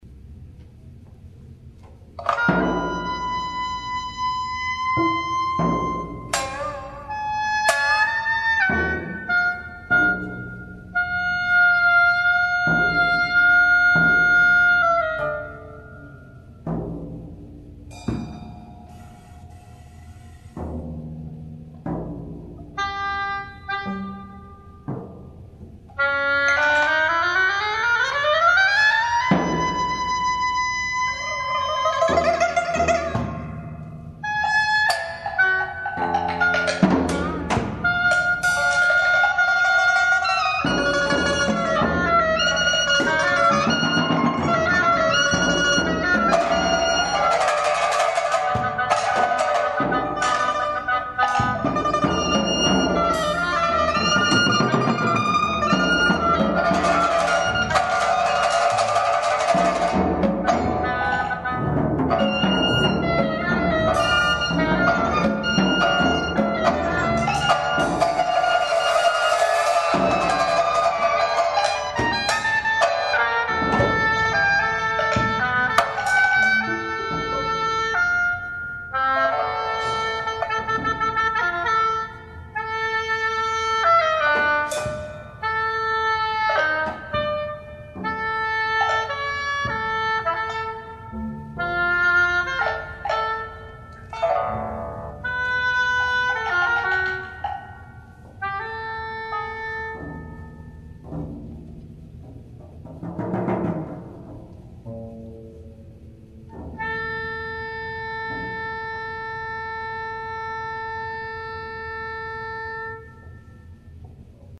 for pipa, oboe, percussion, and bass.